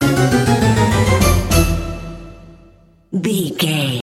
Aeolian/Minor
orchestra
harpsichord
silly
circus
goofy
comical
cheerful
perky
Light hearted
quirky